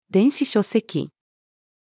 電子書籍-female.mp3